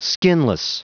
Prononciation du mot skinless en anglais (fichier audio)
Prononciation du mot : skinless